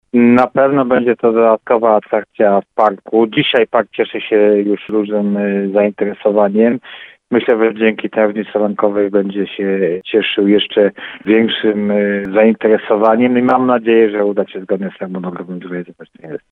Mam nadzieje, że uda się zrealizować te inwestycje zgodnie z harmonogramem – mówił wójt Paweł Ptaszek.